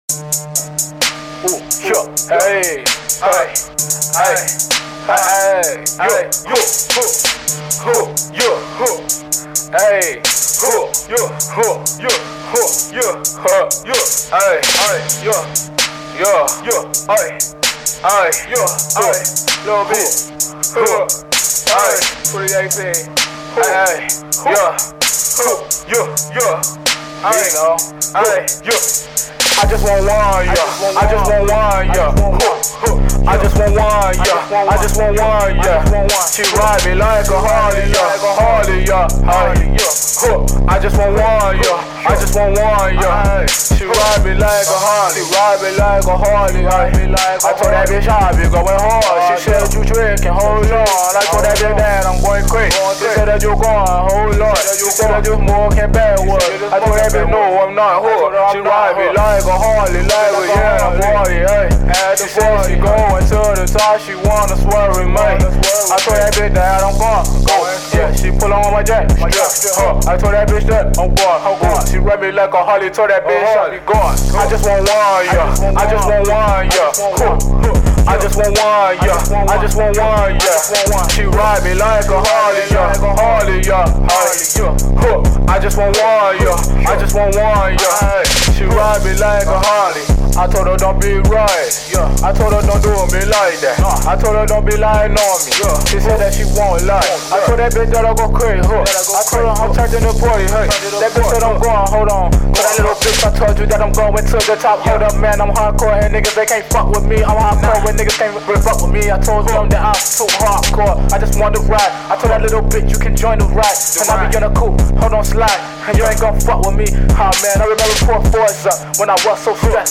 A Project That Has Hard Hits And New Sound Emo Trap!